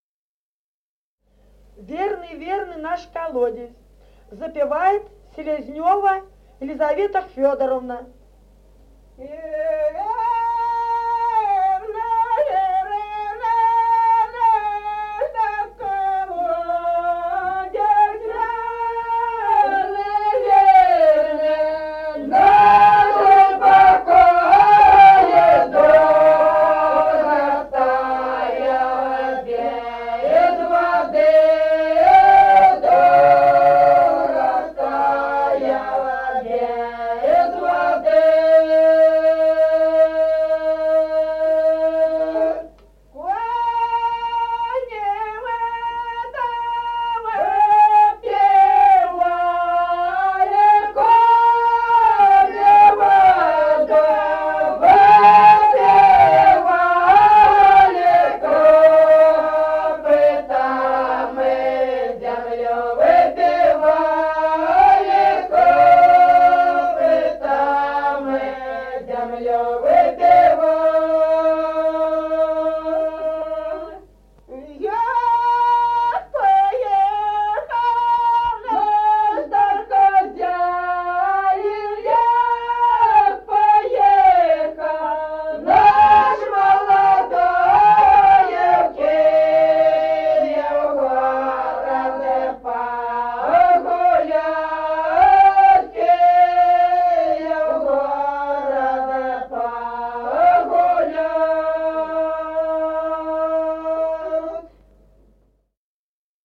Песни села Остроглядово. Верный наш колодезь.